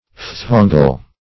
Search Result for " phthongal" : The Collaborative International Dictionary of English v.0.48: Phthongal \Phthon"gal\, a. [Gr.